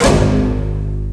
en ce moment je décortique un peu les sons d'instruments contenus dans FFXII pour me créer une banque de sons, mais je suis tombé sur des samples que j'arrive pas a identifier, ils sont souvents utilisés dans les musiques ou y'a de l'action (FFXII a des musiques dans un style symphonique/orchestral), voilà le meme instru sur 2 notes différentes:
c'est quoi exactement ? un pizzicato de contrebasse ?